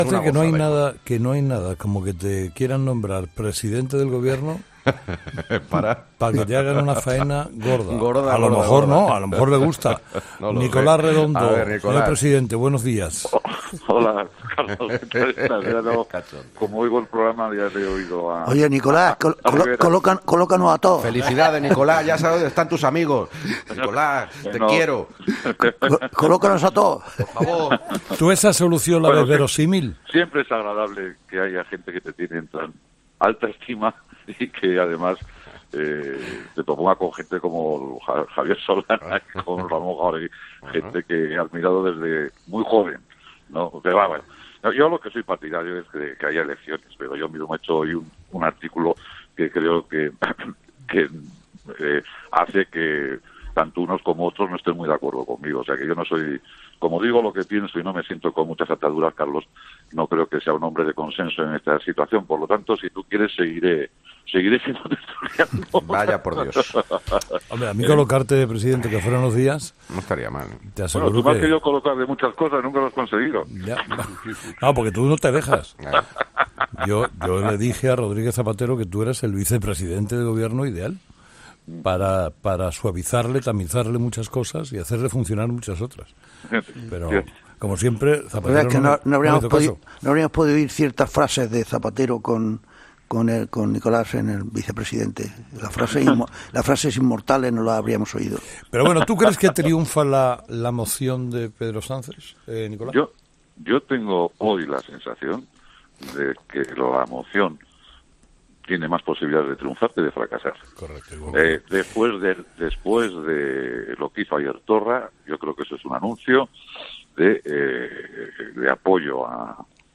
Entrevista a Nicolás Redondo, exsecretario general del PSOE en Euskadi